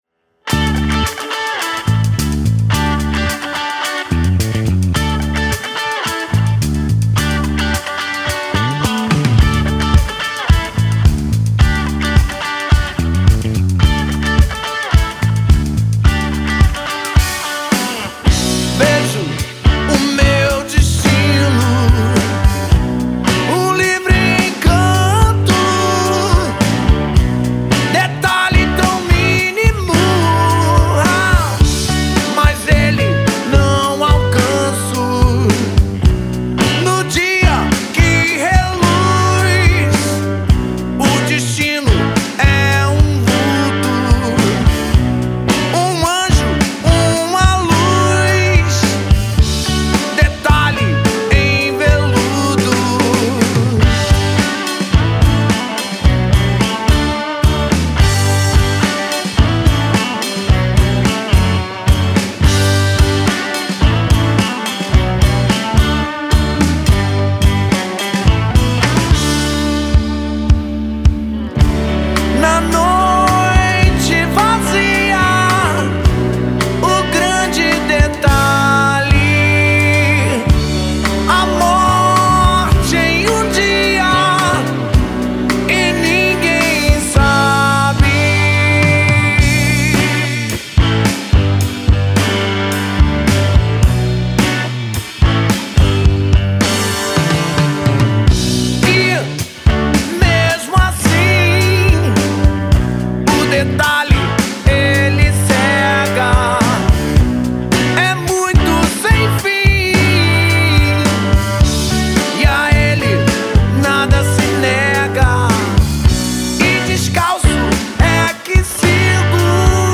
2320   03:49:00   Faixa:     Rock Nacional